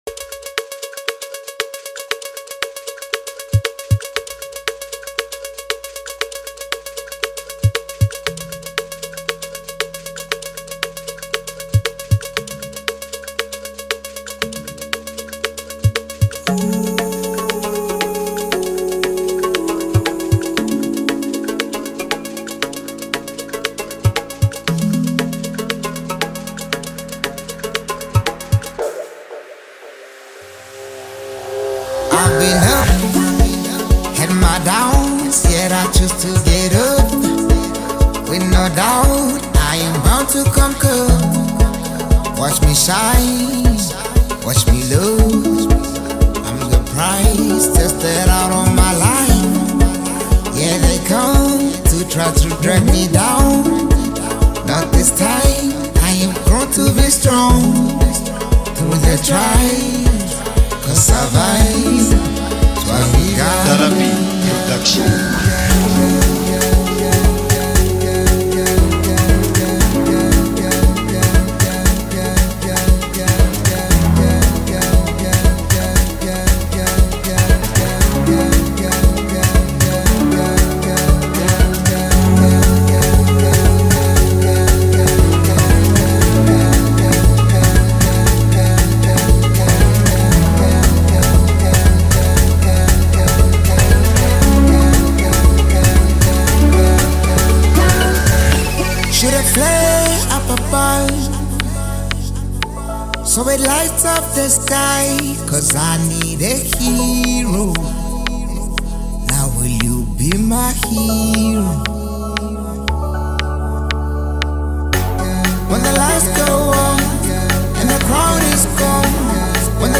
vibrant new song